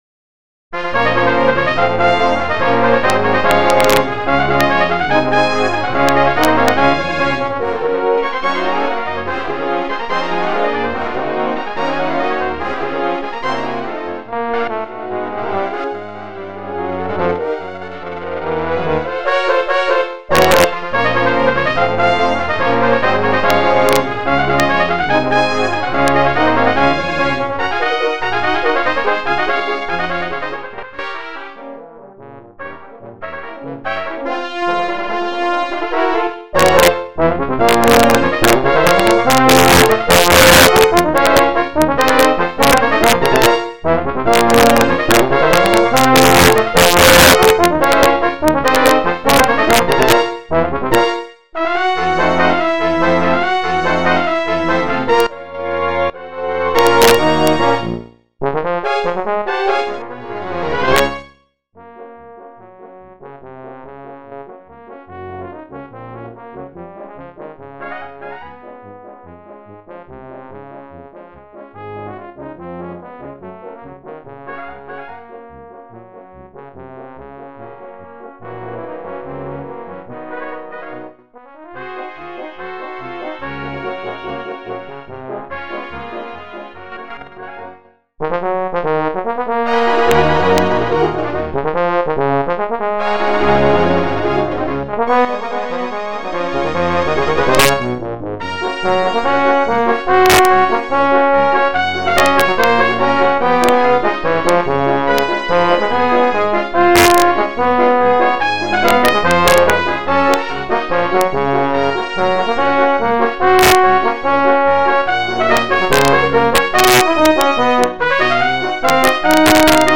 A major work composed for brass band